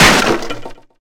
hugeLogCut.wav